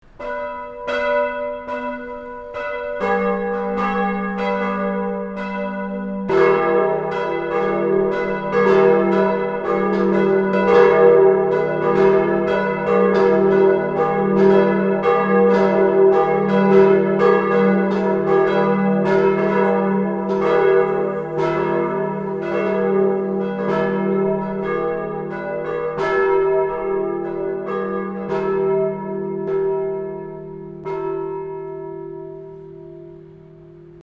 Klingelton Glockenläuten
Kategorien Soundeffekte